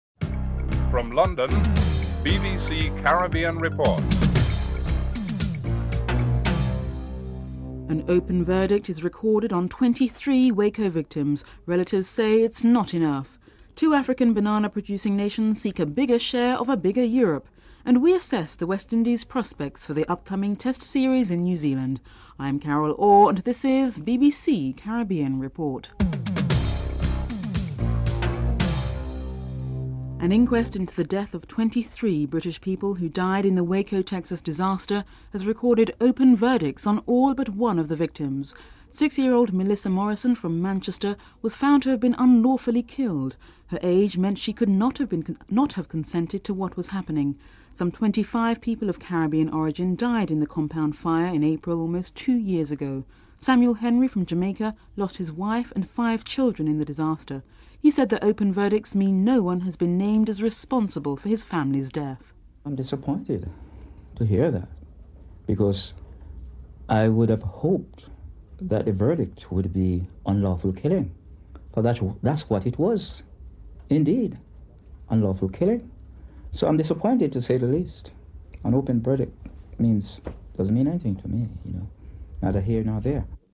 4. Interview with the relatives of the victims of the Waco disaster (01:23-02:11)
7. Report on the request to increase the amount of banana export to Europe by two African countries (04:09-04:44)